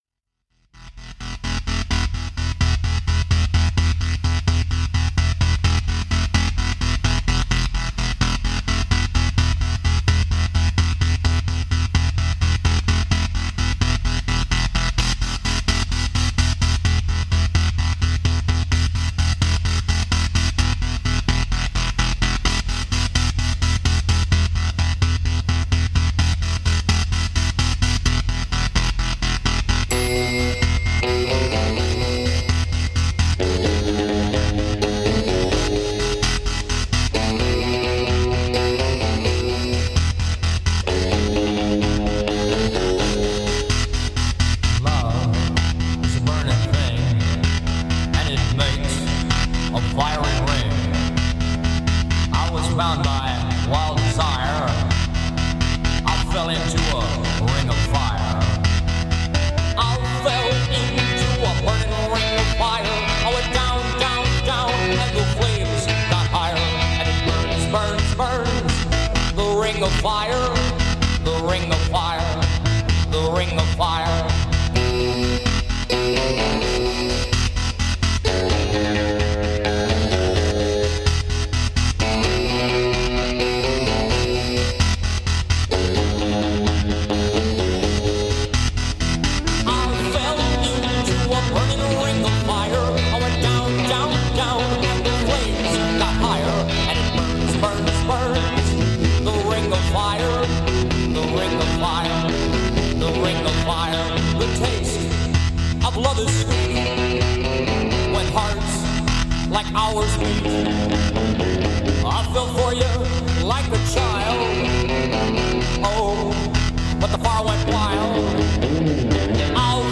Rede & Aufruf Feministische Aktion 8. März: 7:14